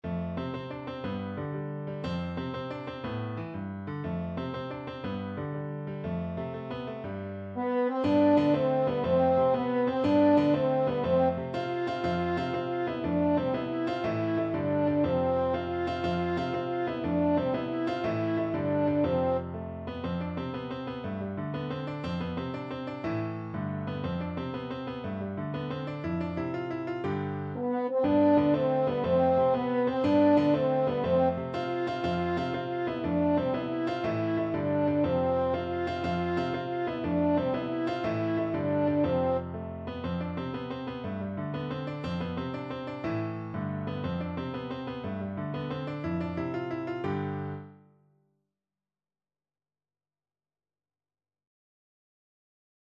Classical Halle, Adam de la J'ai encore une tel paste from Le jeu de Robin et Marion French Horn version
French Horn
6/8 (View more 6/8 Music)
C major (Sounding Pitch) G major (French Horn in F) (View more C major Music for French Horn )
With energy .=c.120
Easy Level: Recommended for Beginners with some playing experience
Classical (View more Classical French Horn Music)